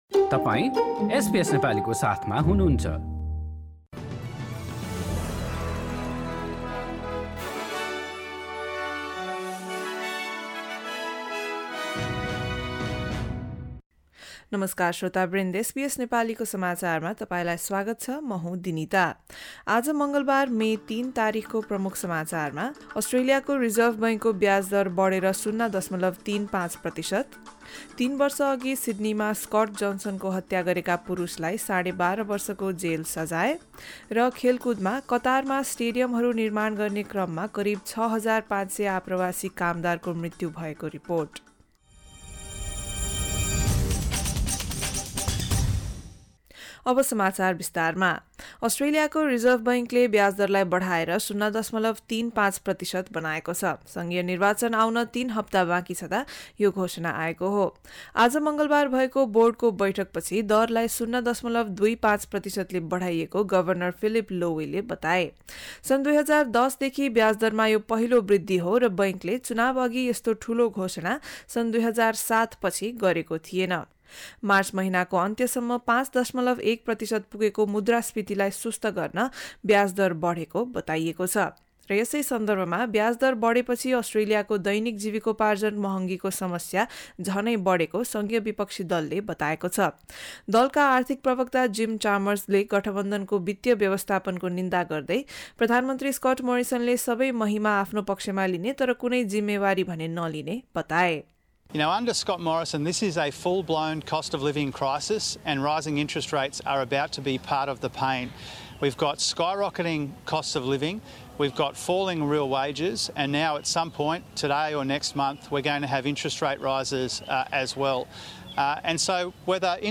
एसबीएस नेपाली अस्ट्रेलिया समाचार: मङ्गलबार ३ मे २०२२